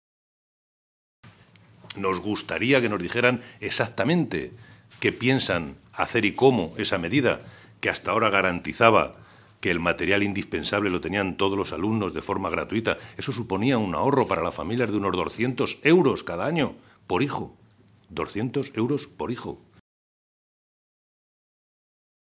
Santiago Moreno, diputado regional del PSOE de Castilla-La Mancha
Cortes de audio de la rueda de prensa